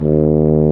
BRS F TUBA03.wav